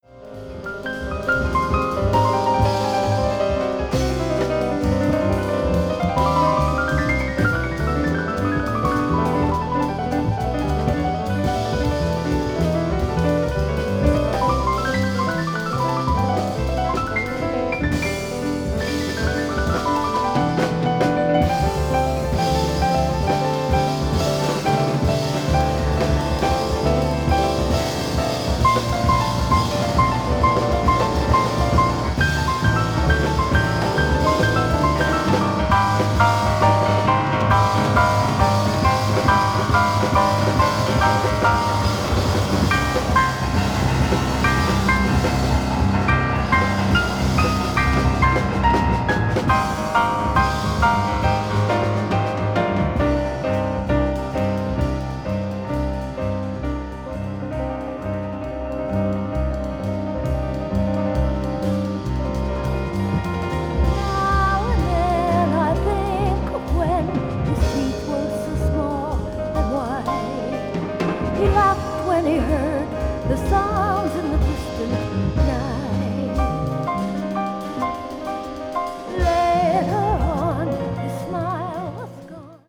media : EX-/EX-(薄いスリキズによるわずかなチリノイズが入る箇所あり,A3後半:軽いプチノイズ2回あり)
contemporary jazz   jazz vocal   spiritual jazz